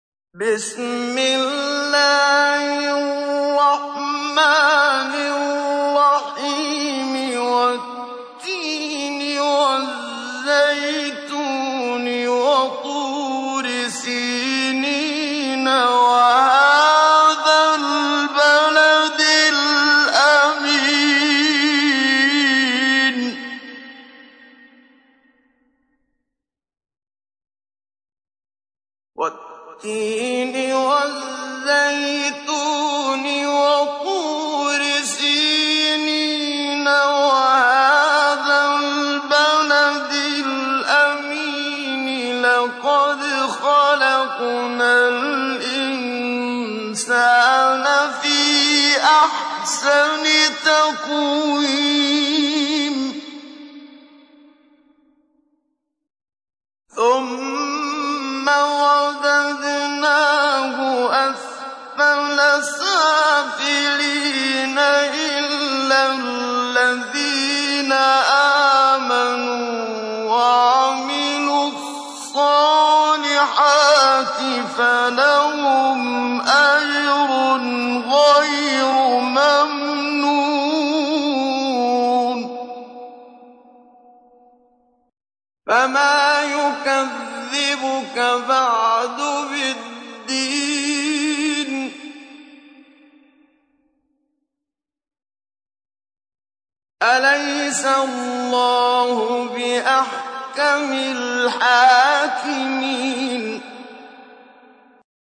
تحميل : 95. سورة التين / القارئ محمد صديق المنشاوي / القرآن الكريم / موقع يا حسين